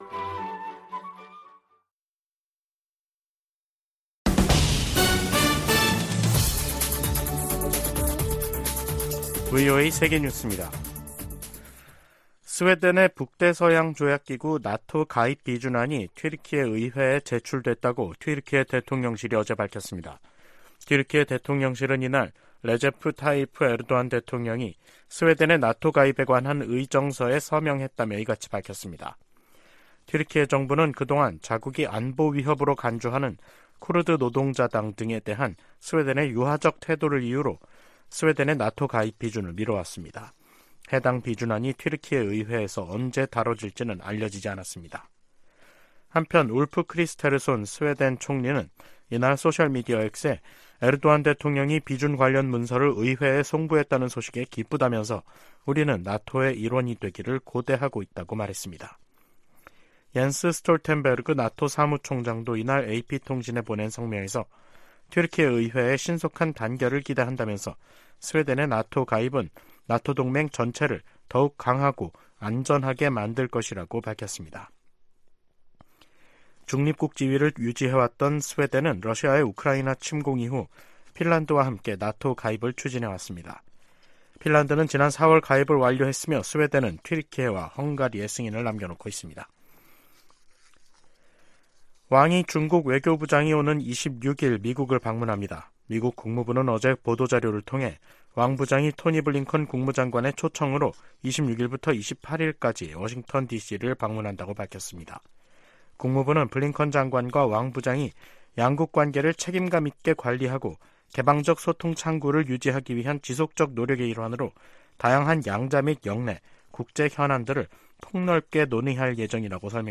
VOA 한국어 간판 뉴스 프로그램 '뉴스 투데이', 2023년 10월 23일 2부 방송입니다. 북한 주민 4명이 소형 목선을 타고 동해 북방한계선(NLL)을 통과해 한국으로 넘어 왔습니다. 미국 정부가 북한과 러시아의 무기 거래 현장으로 지목한 북한 라진항에 또다시 대형 선박이 정박한 모습이 포착됐습니다.